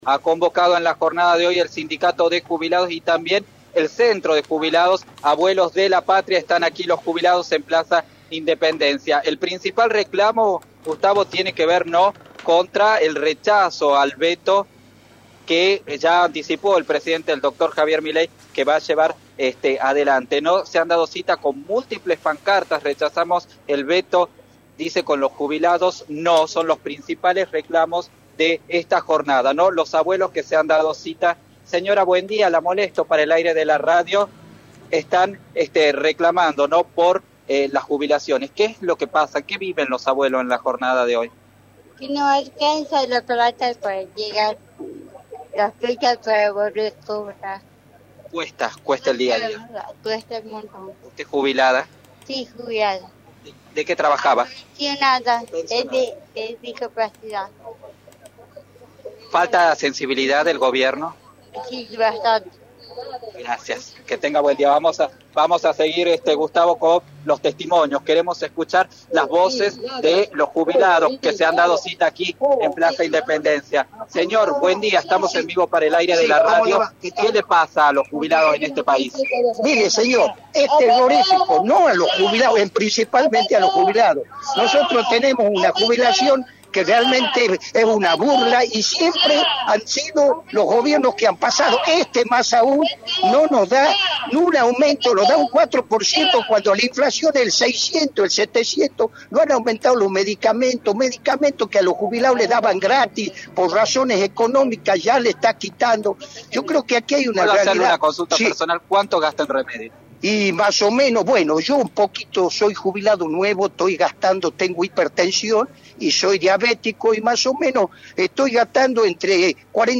“Tenemos una jubilación que es una burla, es una vergüenza lo que está pasando con nosotros” fue uno de los mensajes que más se repitió en la protesta realizada en Plaza Independencia este jueves.
Escuchar a los jubilados